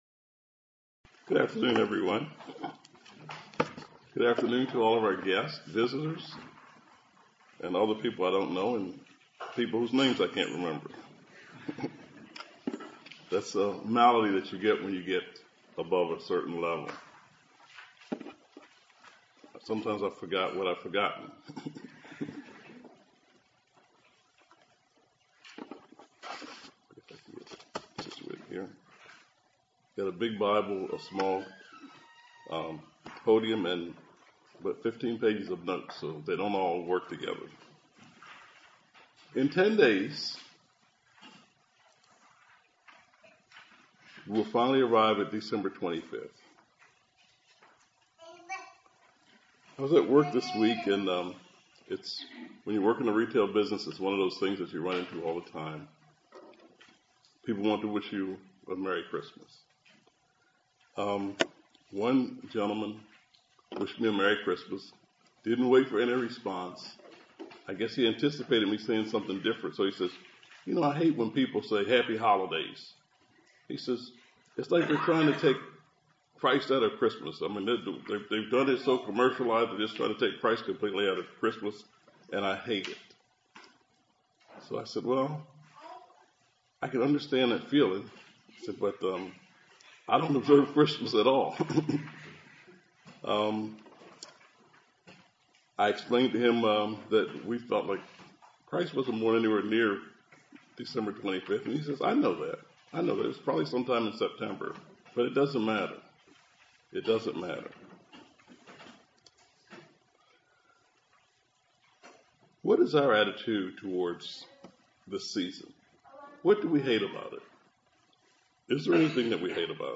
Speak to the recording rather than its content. Given in Columbia, MD